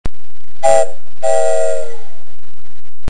trainStereoMPeg.wav